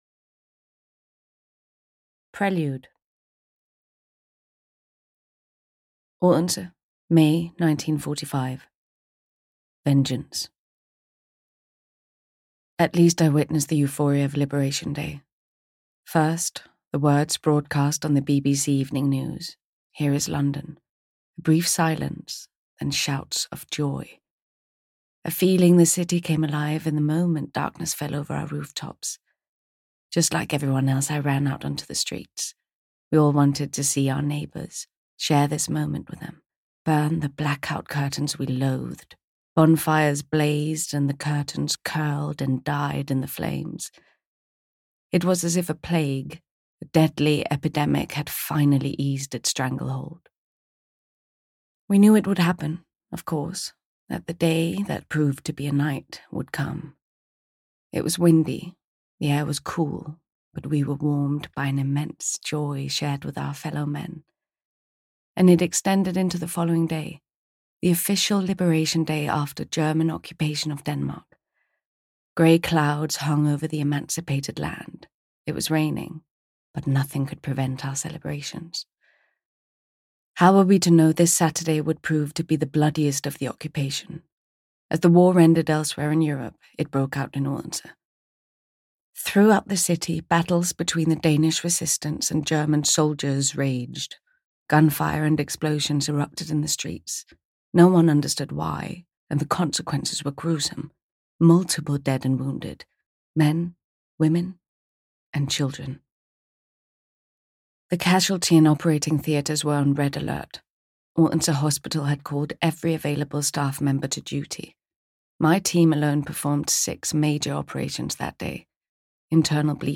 The Nurse from the Big White Ship (EN) audiokniha
Ukázka z knihy